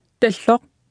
Speech synthesis Martha to computer or mobile phone